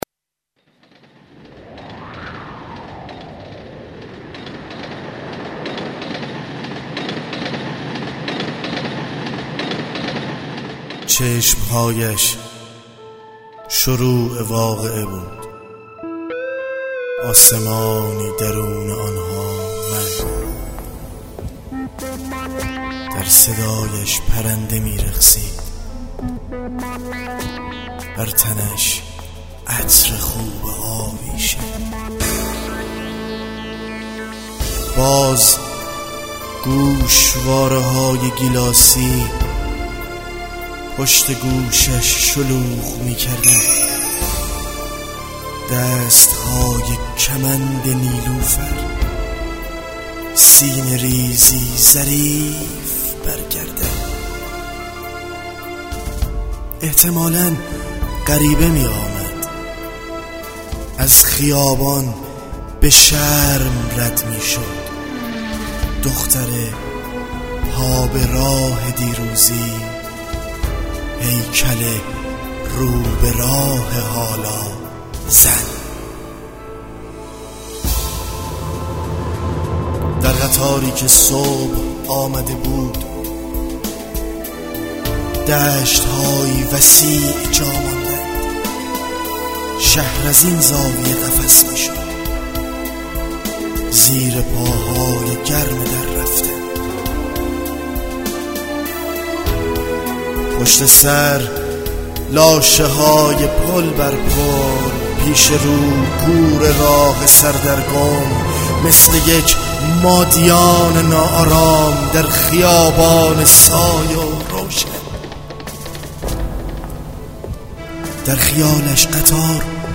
دانلود دکلمه مادیان با صدای علیرضا آذر
اطلاعات دکلمه